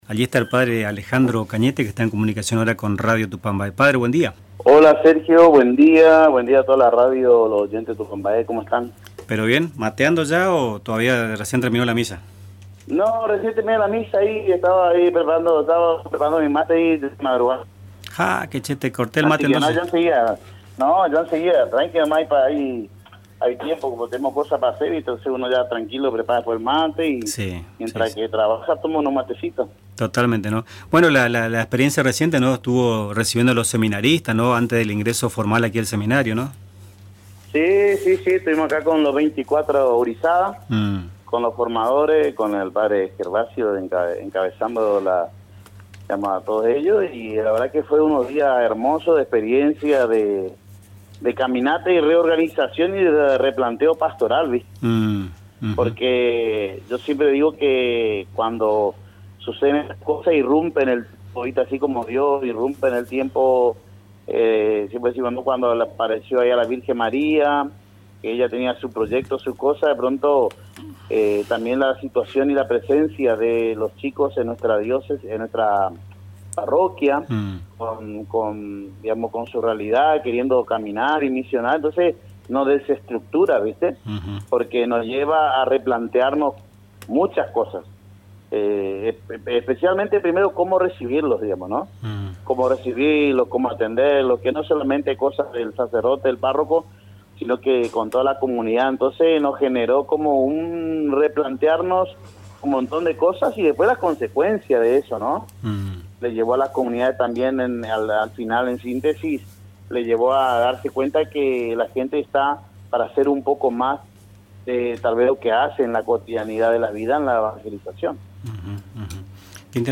En diálogo con Nuestras Mañanas